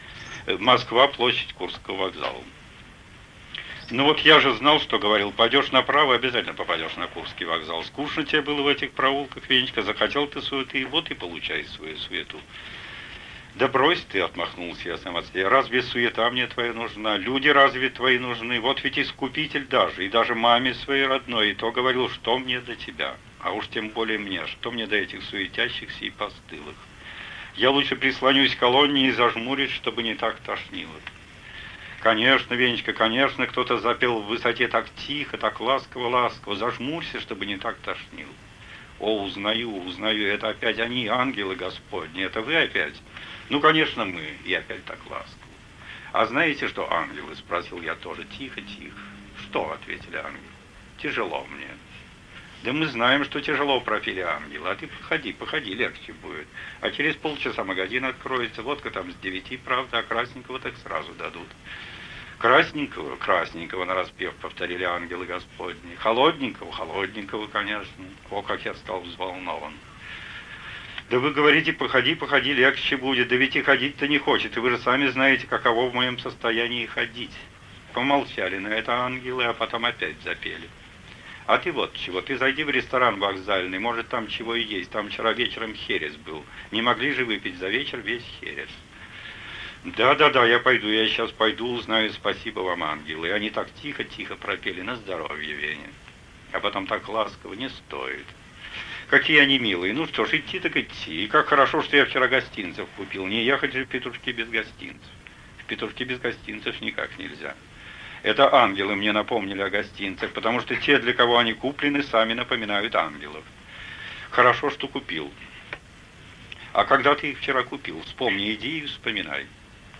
В этом КП и далее вы можете прослушать каждую главу целиком в исполнении автора.